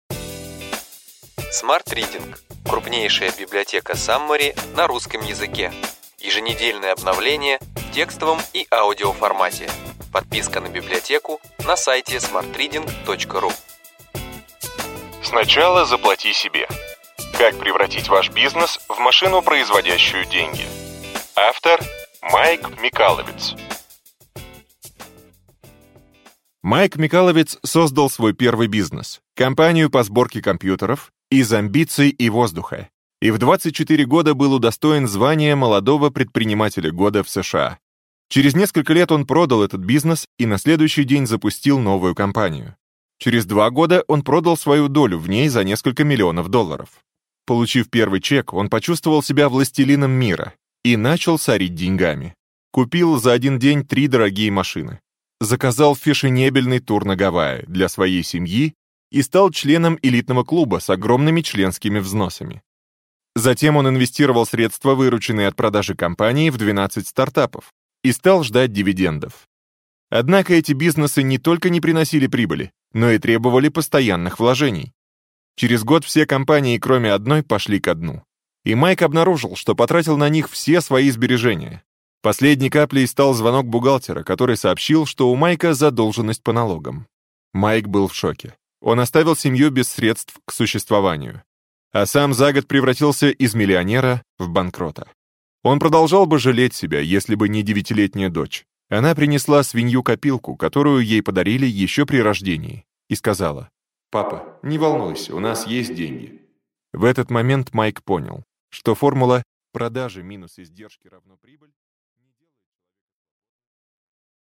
Аудиокнига Ключевые идеи книги: Сначала заплати себе. Превратите ваш бизнес в машину, производящую деньги.